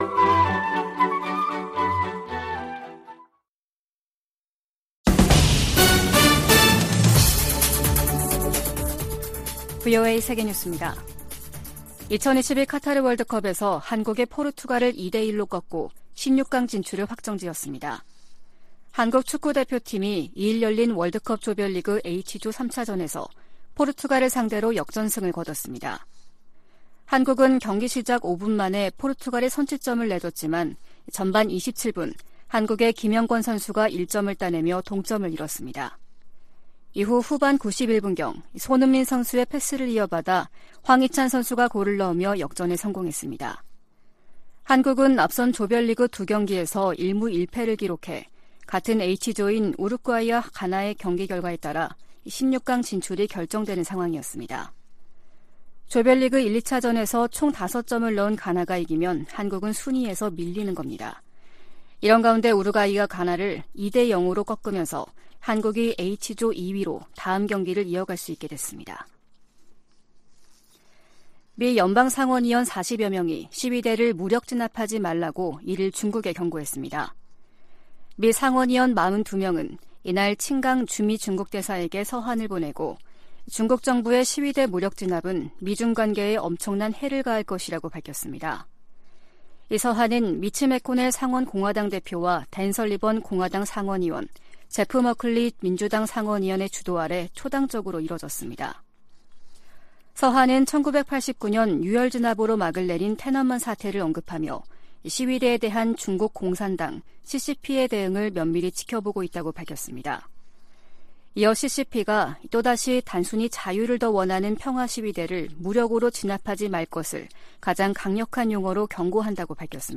VOA 한국어 아침 뉴스 프로그램 '워싱턴 뉴스 광장' 2022년 12월 3일 방송입니다. 미국 정부가 북한 정권의 잇따른 탄도미사일 발사에 대응해 노동당 간부 3명을 제재했습니다. 한국 정부도 49일만에 다시 북한의 핵과 미사일 개발 등에 관여한 개인과 기관들을 겨냥해 독자 제재를 가하는 등 미한 공조 대응이 강화되고 있습니다.